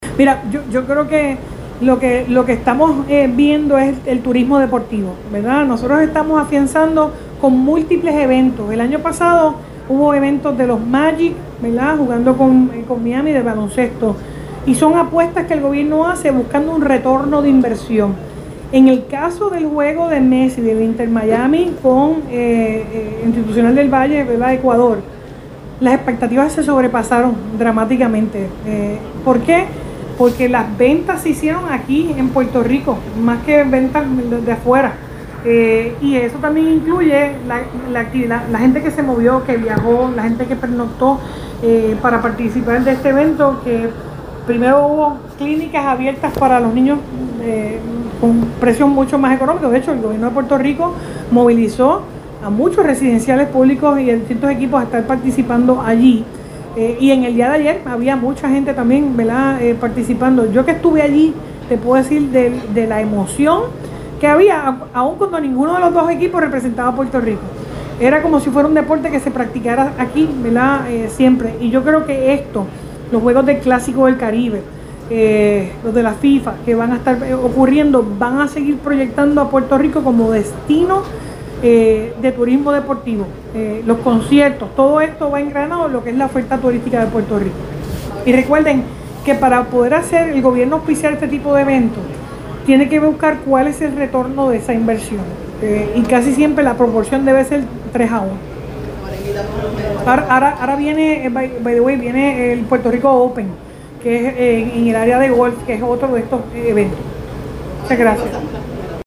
Gobernadora reconoce aportación del juego de futbol con Lionel Messi en Bayamón para el turismo deportivo (sonido)